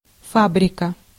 Ääntäminen
IPA : /mɪl/